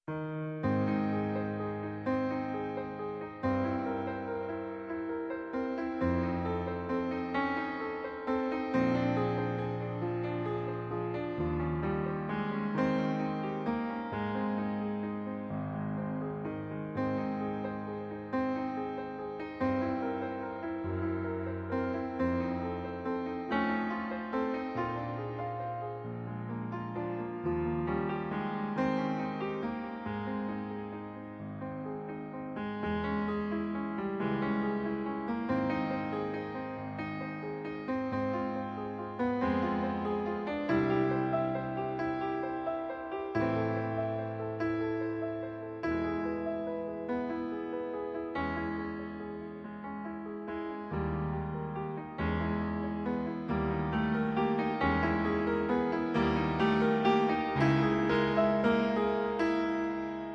チャ ⤵ ーン　チャ ⤴ ～ン　チャ～ン　チャ～ン
チャラン ⤵　チャラン ⤵　チャラン ⤵